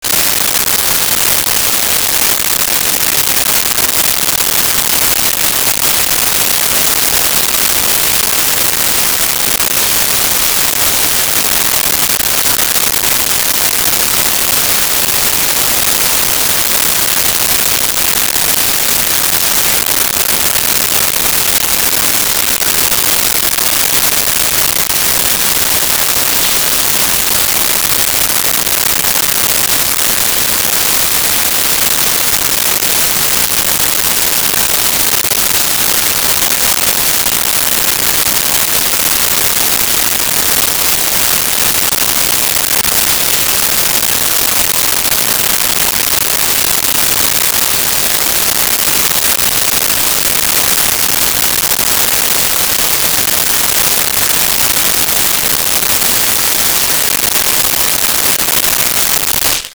Shower 1
shower-1.wav